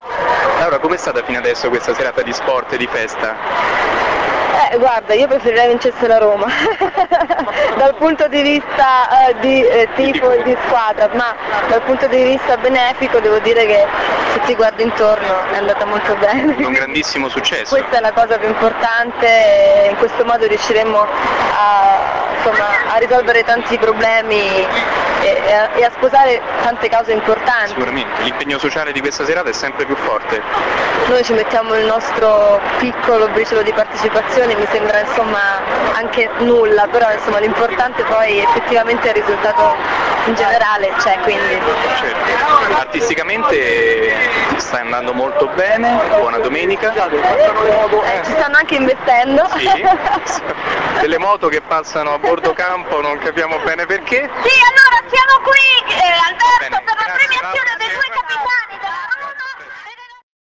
Speciale Derby del Cuore - INTERVISTE AI PERSONAGGI